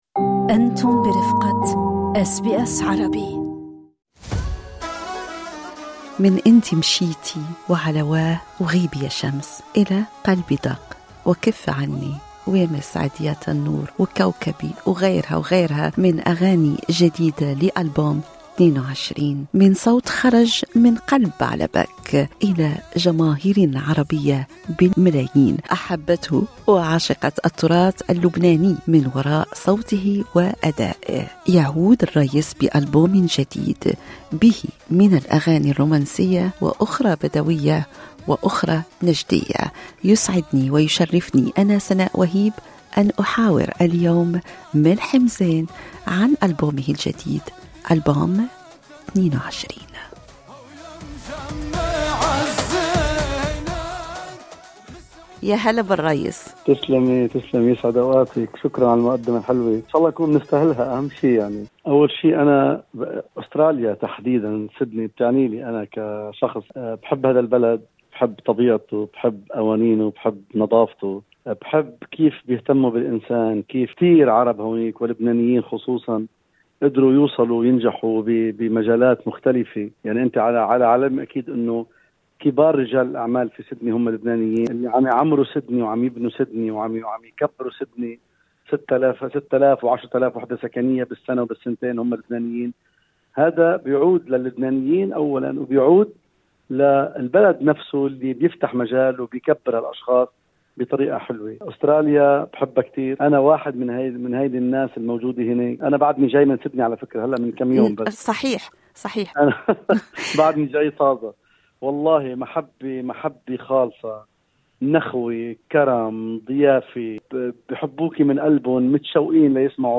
في مقابلةٍ خاصة مع إذاعة SBS عربي، تحدّث النجم اللبناني عن زياراته المتكررة لأستراليا، البلد الذي يكنّ له محبة كبيرة، والذي يحتضنه هو وفنه كل عام.